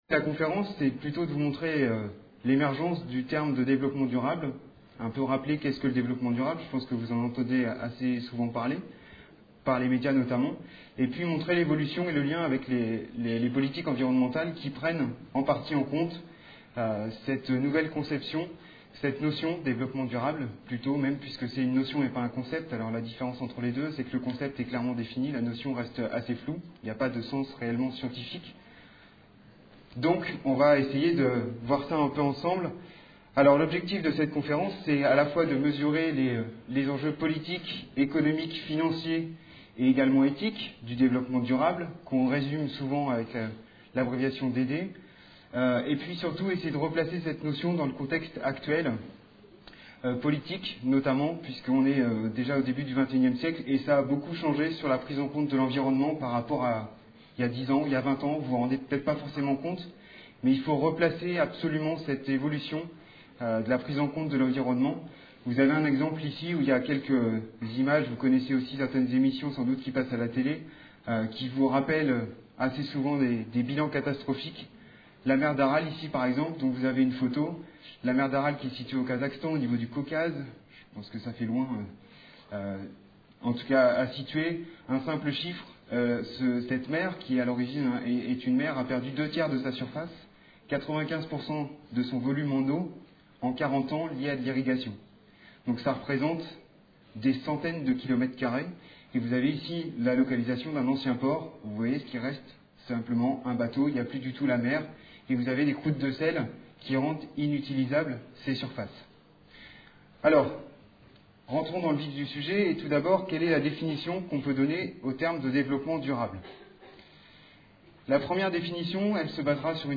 Une conférence de l'UTLS au Lycée L'environnement